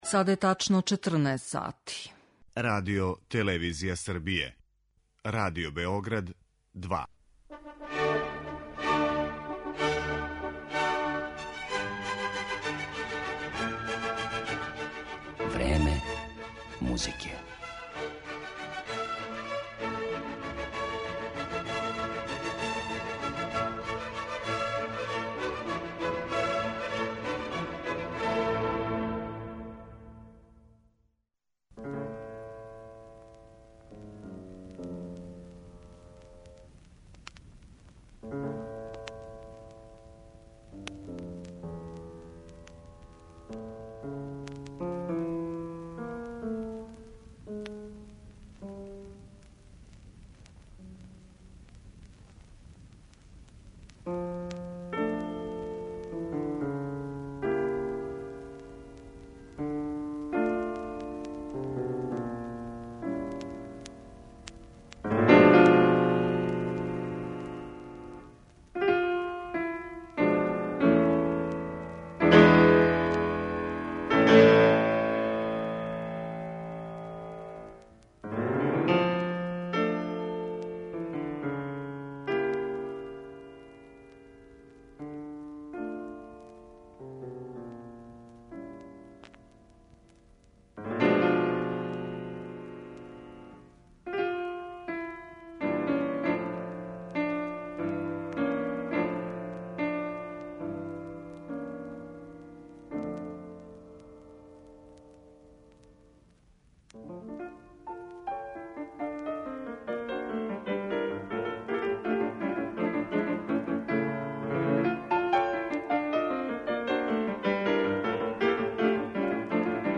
Клавирски дуо